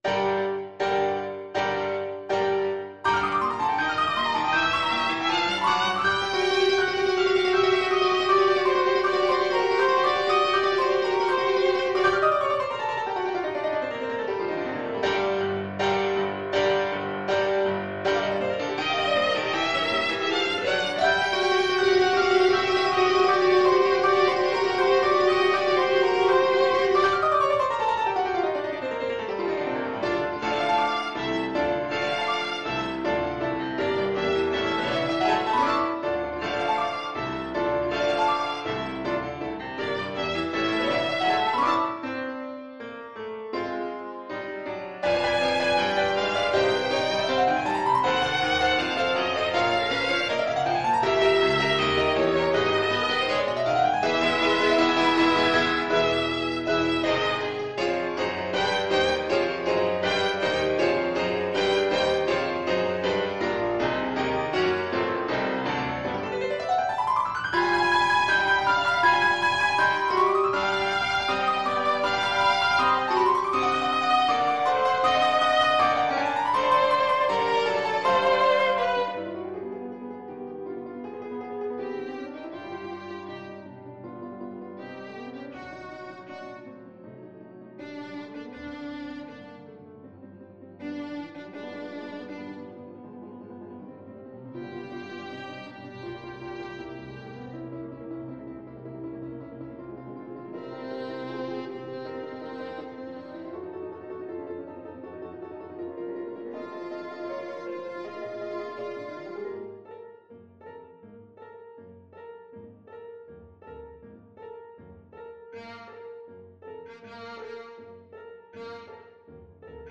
4/4 (View more 4/4 Music)
Allegro agitato (=80) (View more music marked Allegro)
Classical (View more Classical Violin Music)